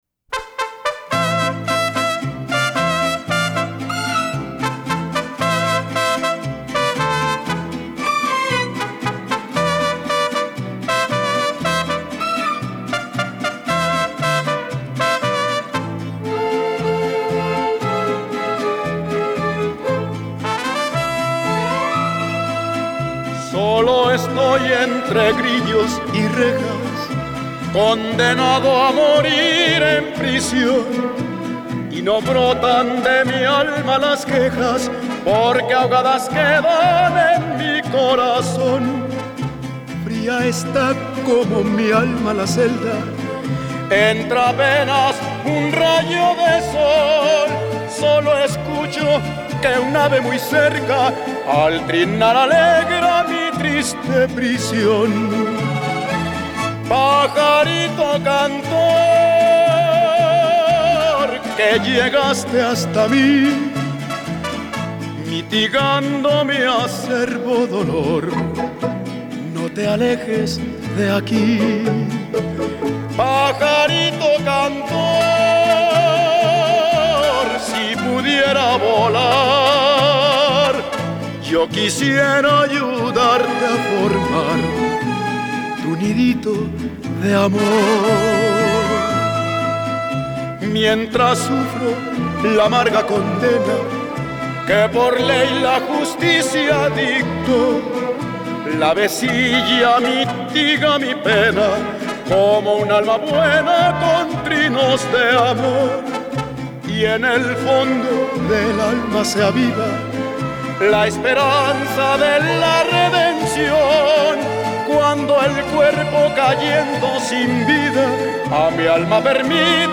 bolero ranchero
Tango ranchero cabal.